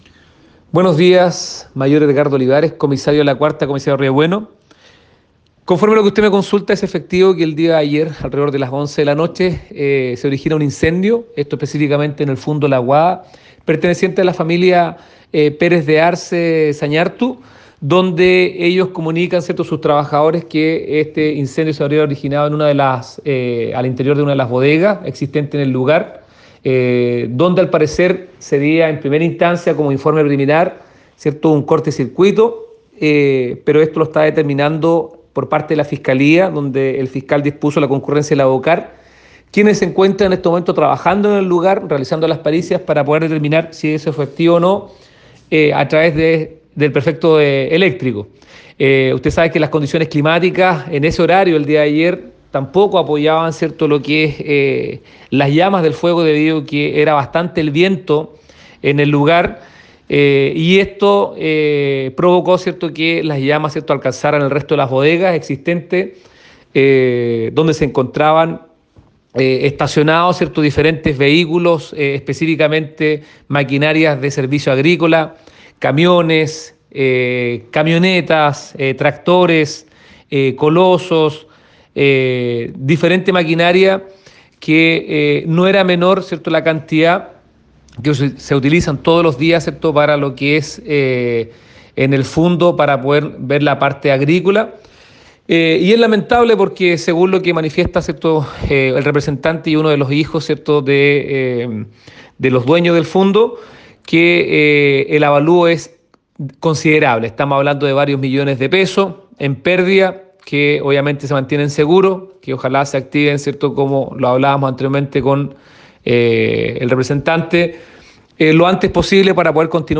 Mayor de Carabineros.